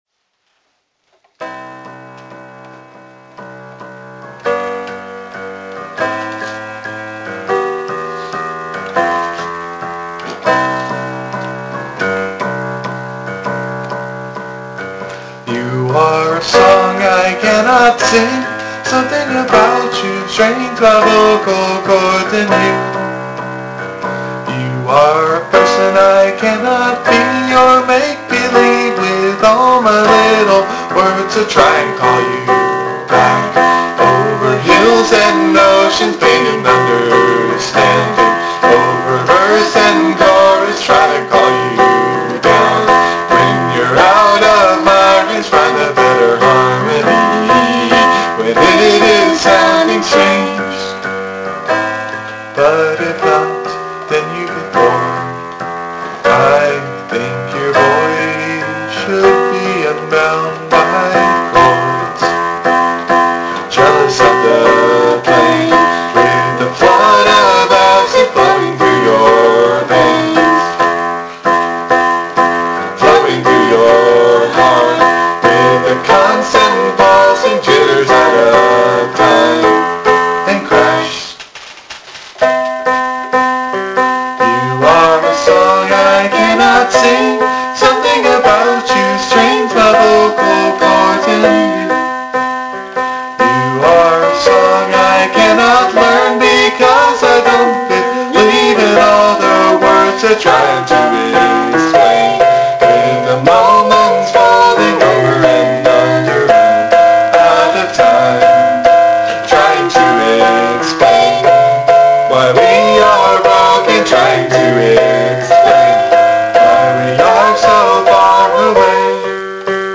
(Vocal harmony version below the lyrics.)